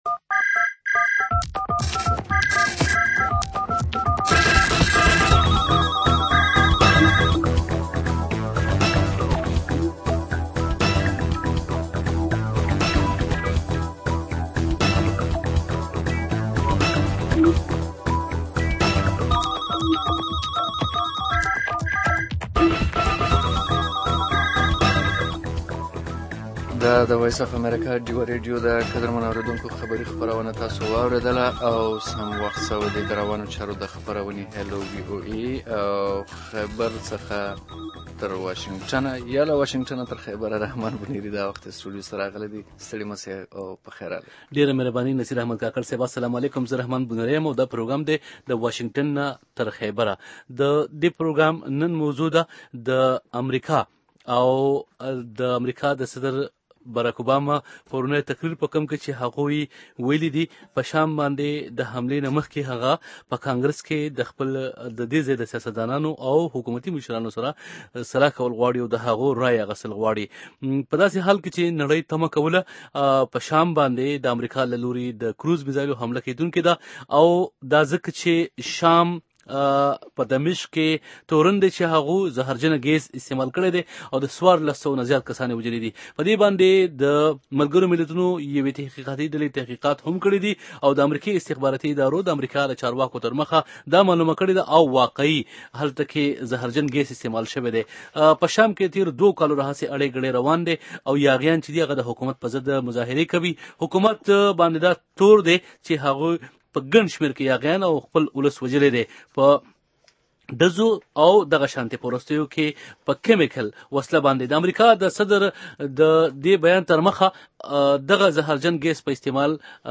د امریکې په شام ممکنه حمله او د ډیوه ریډیو مباحثه